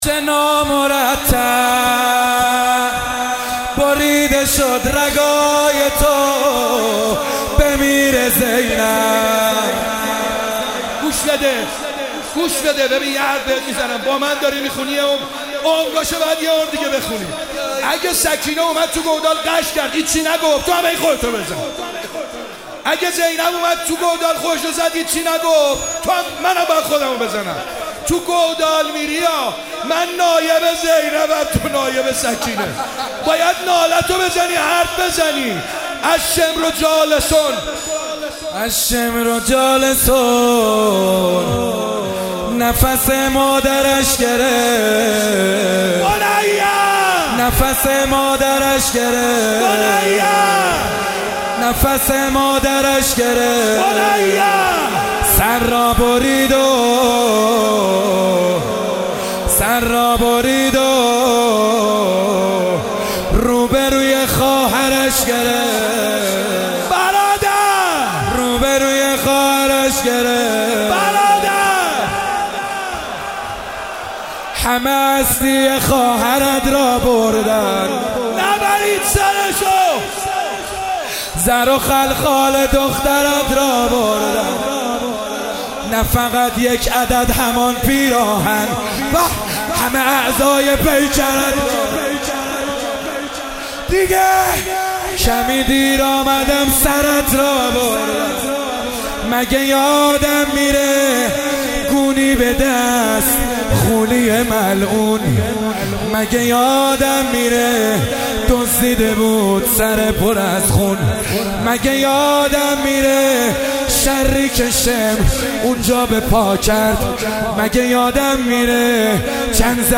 شب عاشورا محرم97 - روضه